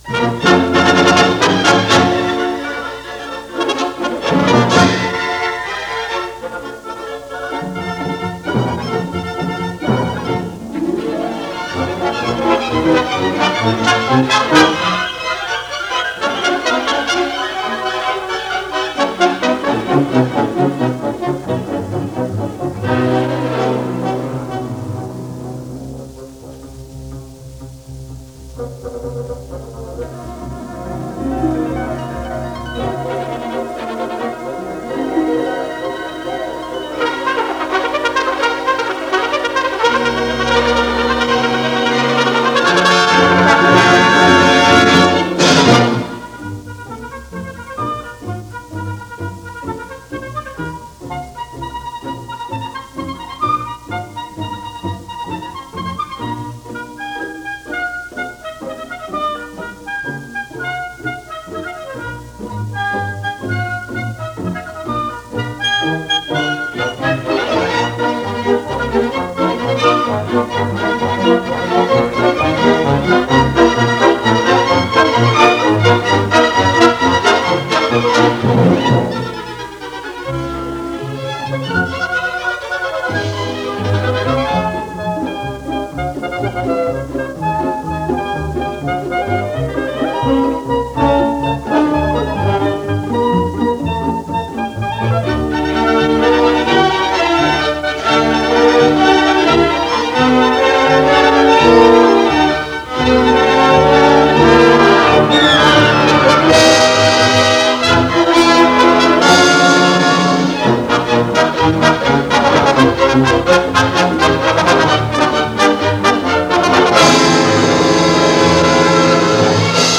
Théo Ysaÿe – “Fantaisie sur un thème populaire wallon” pour grand orchestre, op. 13 (publ. 1907) – NBC Studio Orchestra – Dr,. Frank Black, cond
The music of Théo Ysaÿe as performed in this Concert Of Nations broadcast by the NBC Studio Orchestra conducted by Dr. Frank Black in this broadcast from August 1946.
While he inherited initially the style of César Franck, his later work revealed a clear influence of impressionism.